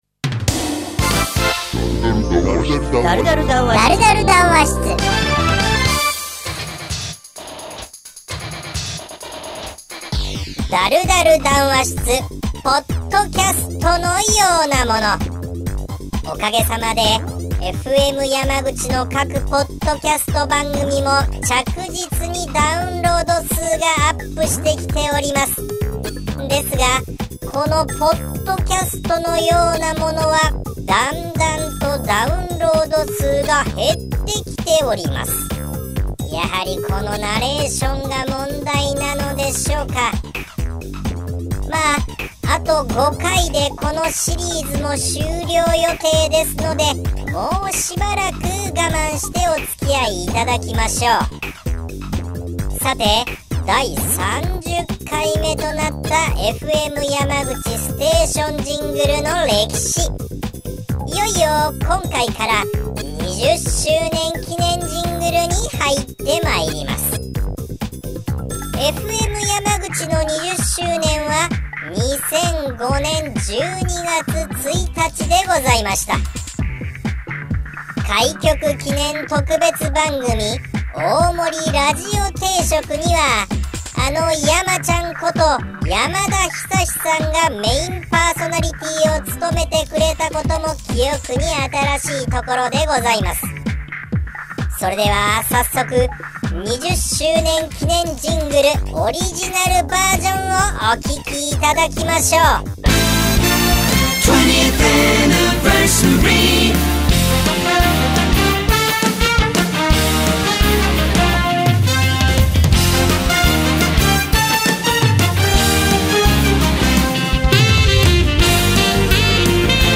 ナレーションが聴き辛いというご指摘がありましたので、エフェクトのかかり具合をかなり少なくしてみました。